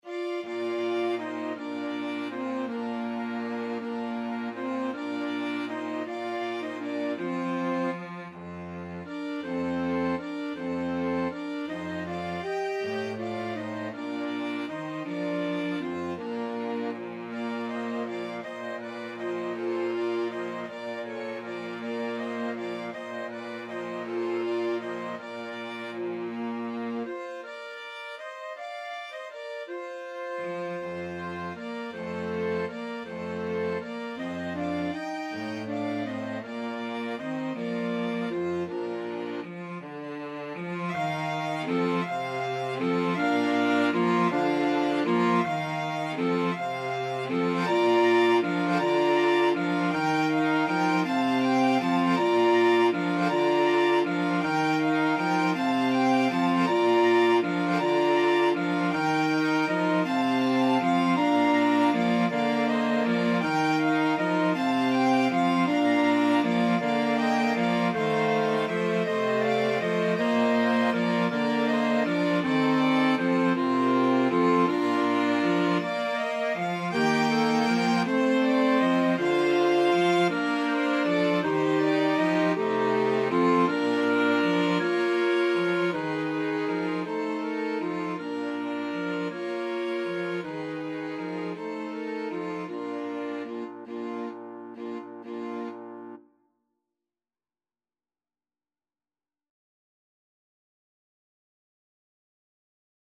Clarinet
Alto Saxophone
Sicilian carol
~ = 80 Allegro moderato (View more music marked Allegro)
6/8 (View more 6/8 Music)